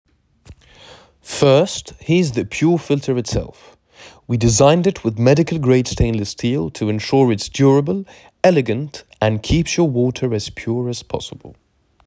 Accent anglais 3